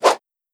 Sword.wav